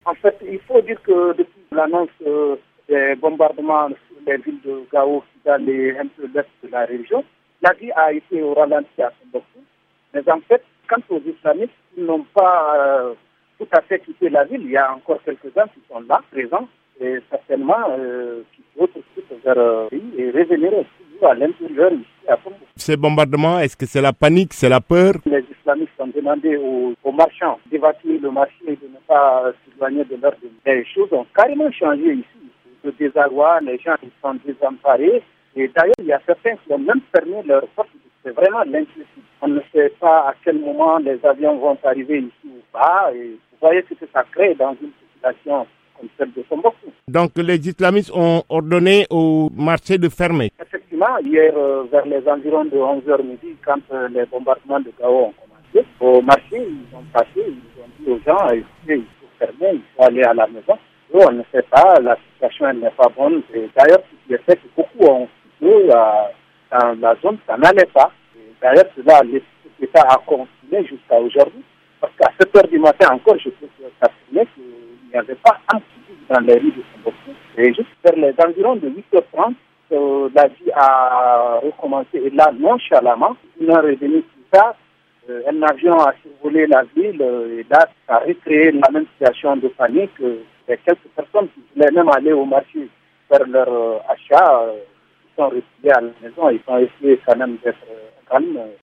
Notre correspondant à Tombouctou